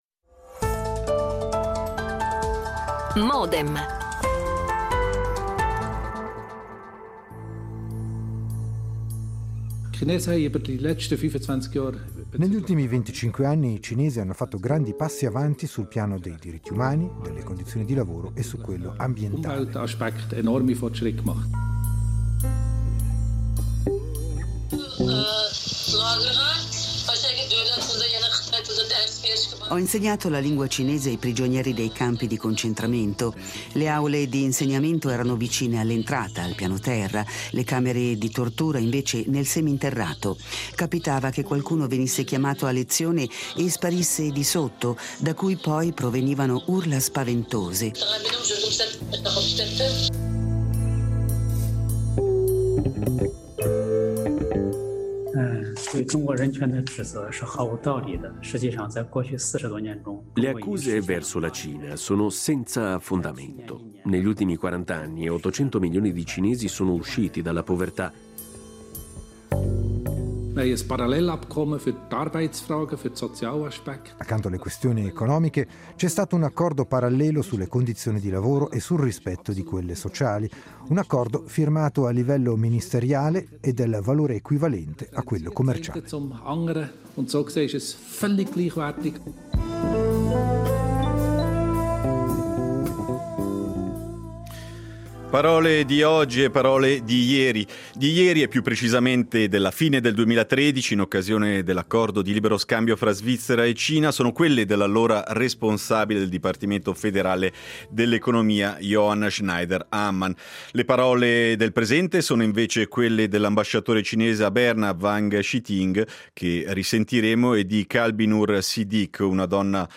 In registrato qualche minuto con l’ambasciatore cinese a Berna Wang Shihting
L'attualità approfondita, in diretta, tutte le mattine, da lunedì a venerdì